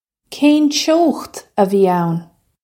Kayn choe-kht uh vee own?
This is an approximate phonetic pronunciation of the phrase.